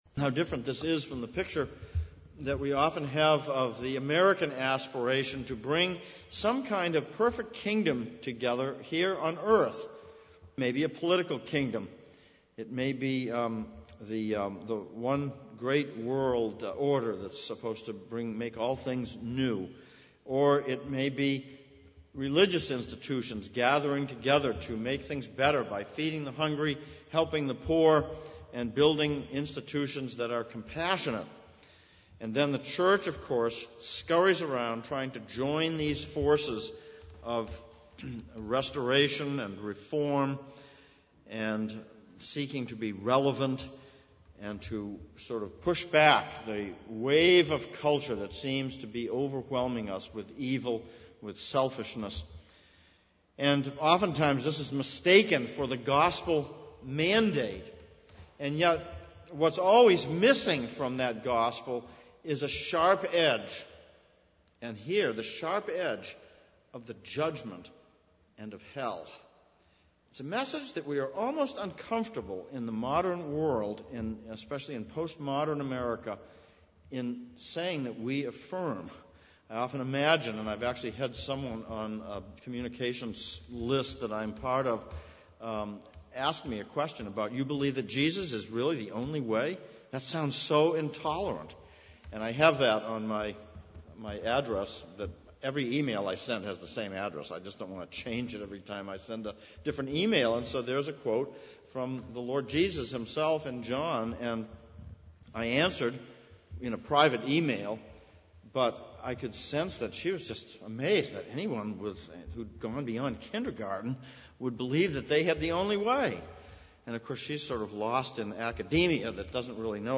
Psalm 75:1-Revelation 75:10 Service Type: Sunday Evening « 29.